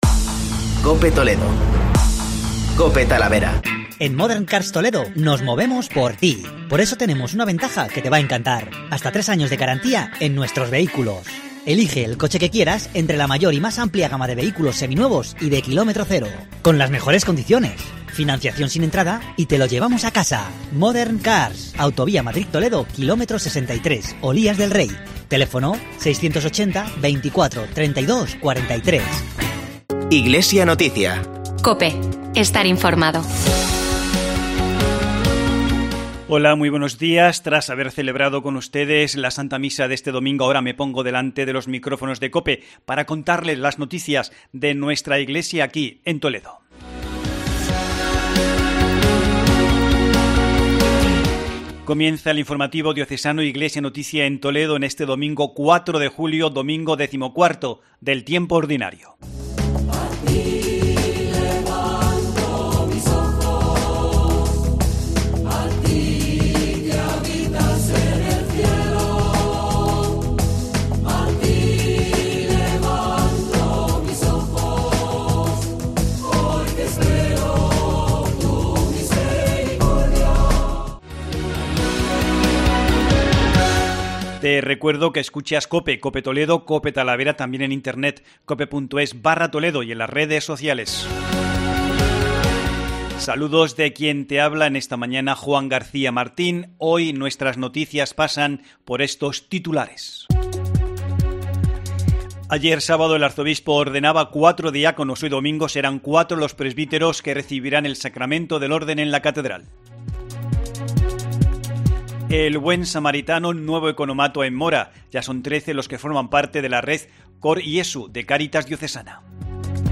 informativo Diocesano